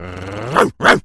Animal_Impersonations
wolf_bark_03.wav